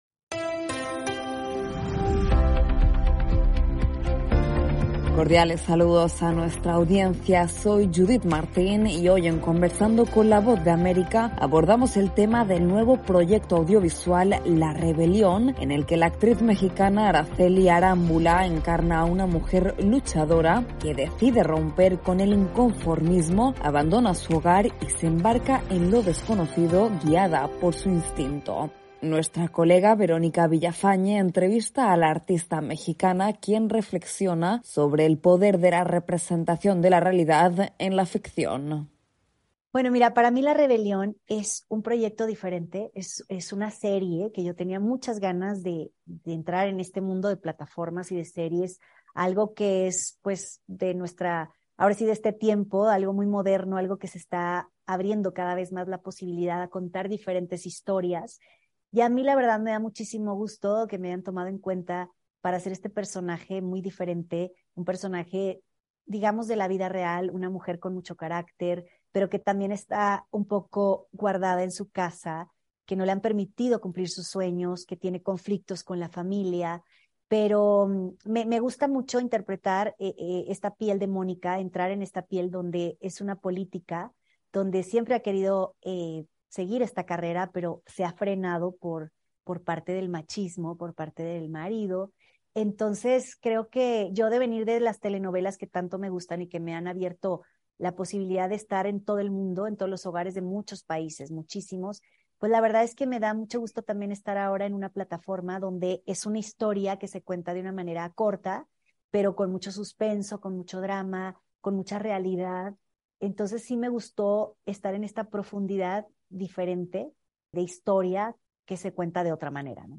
Conversamos con la actriz y cantante mexicana, Aracely Arámbula, ofreciendo sus impresiones sobre “La Rebelión”, la nueva serie que protagoniza.